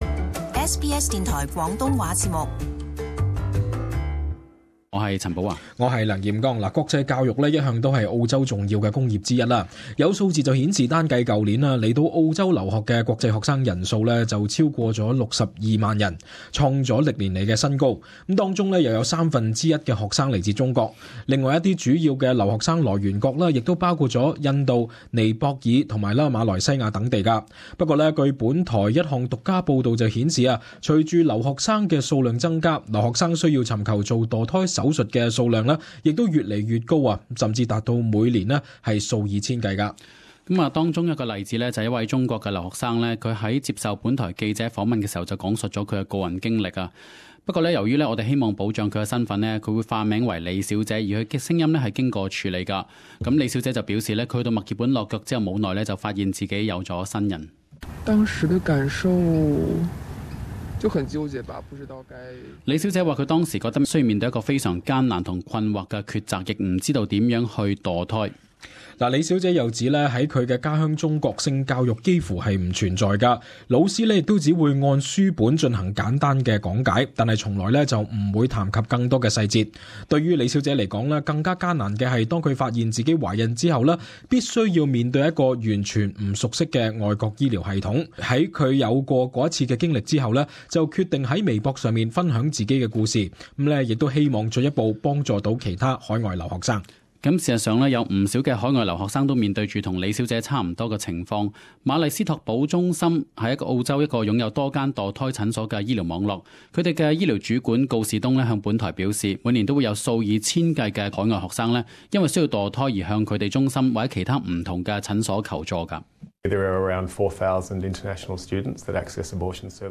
【時事報導】澳洲留學生墮胎情況越趨嚴重